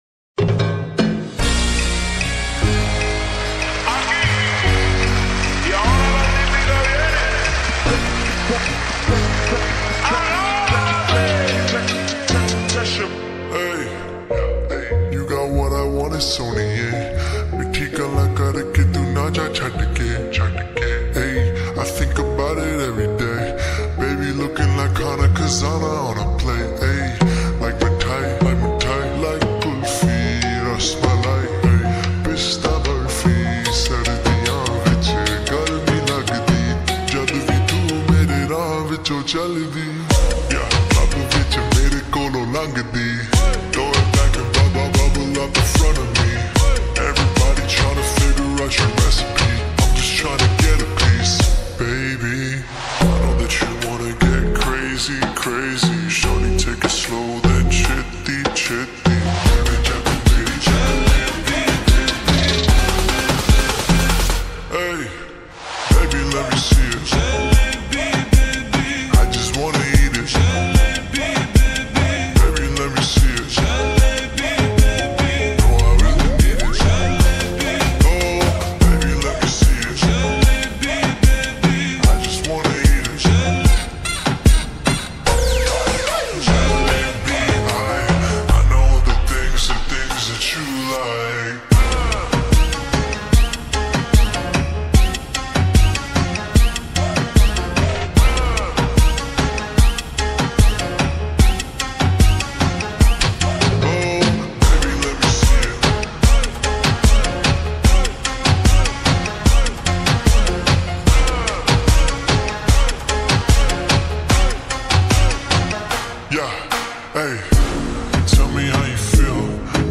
نسخه Slowed و آهسته شده
شاد